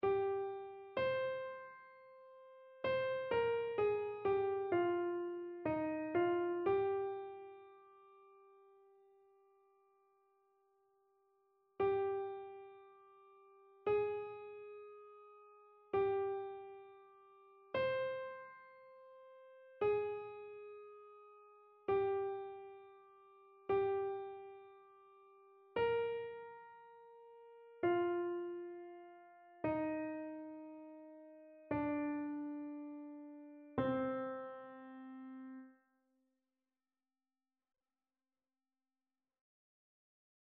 Chœur
annee-b-temps-ordinaire-22e-dimanche-psaume-14-soprano.mp3